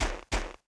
Index of /App/sound/monster/ice_snow_witch
walk_1.wav